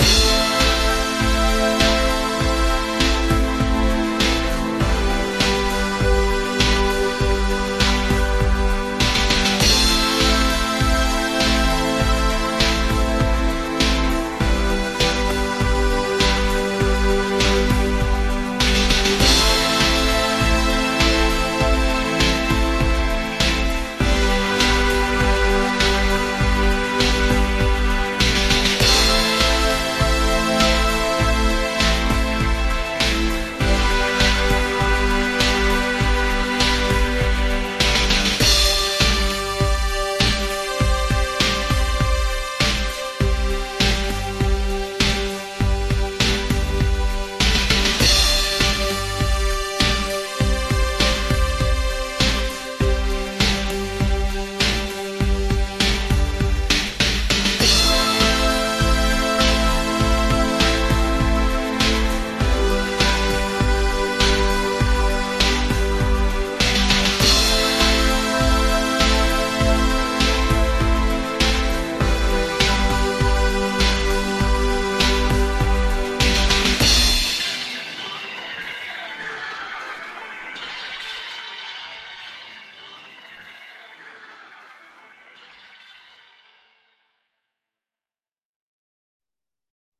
synthpop_0507_1.mp3